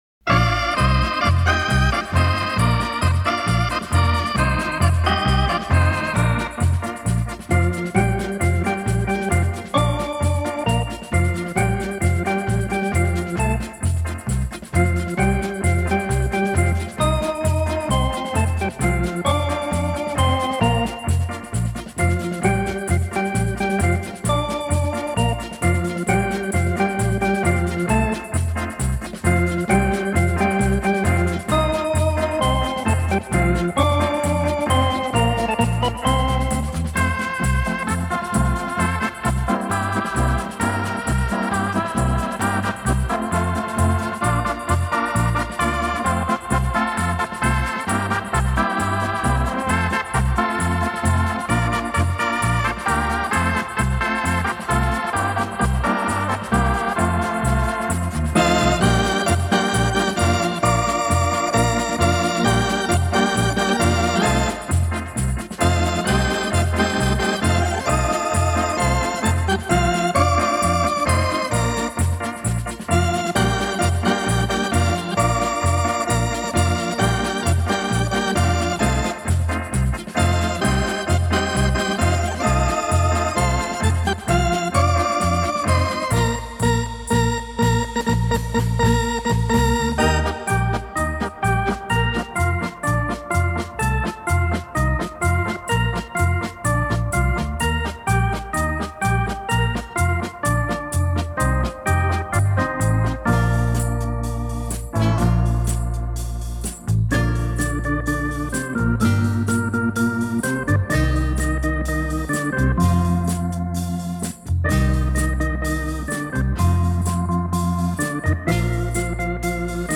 且每段音乐由两到三个音乐小节组成，每个小节之间非常连贯，形成一个完整的乐曲